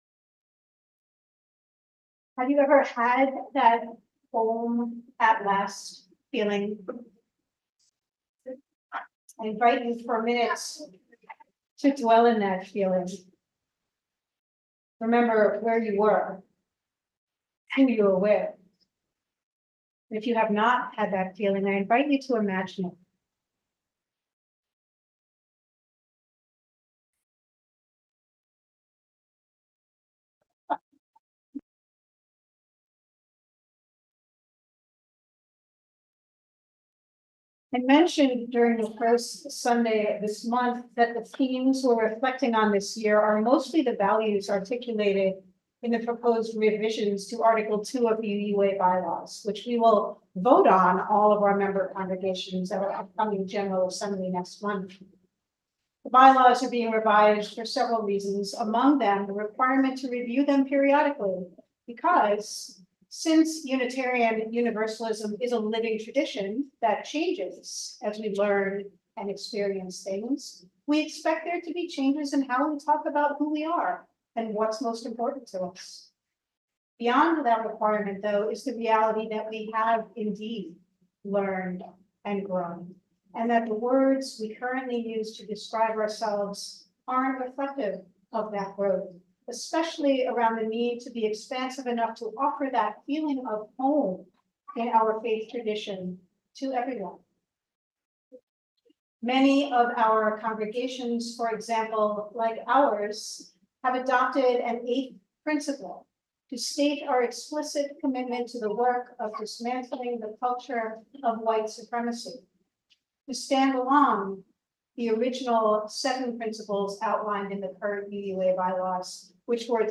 Order of Service